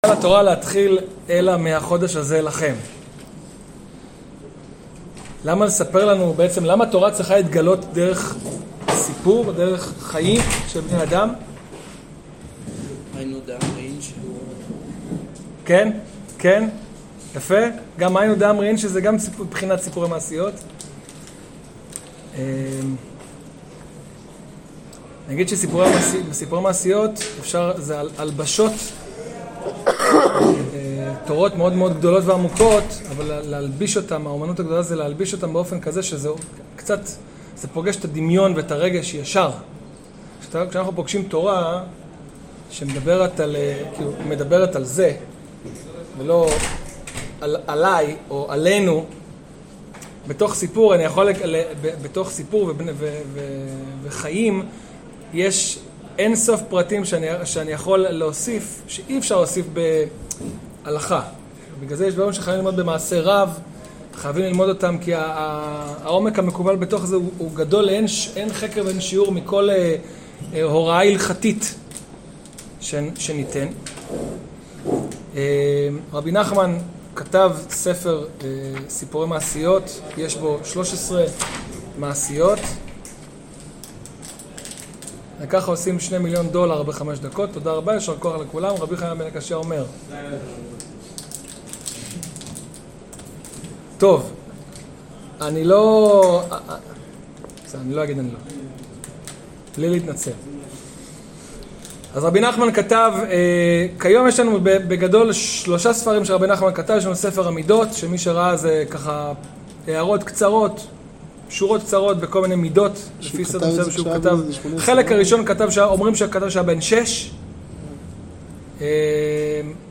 באמצע השיעור היתה אזעקה והשיעור עבר למקלט, עלול להיות הבדל בשמע אחר כך